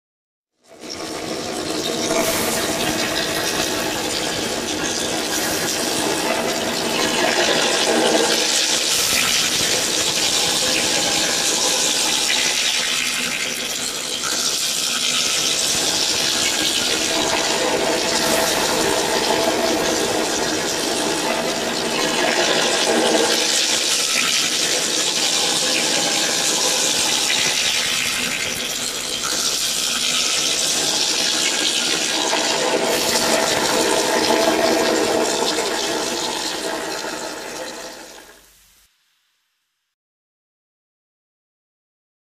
Eerie Synthetic Rain Ambience With Acid Hiss On Contact.